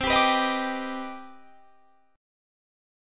Listen to CmM9 strummed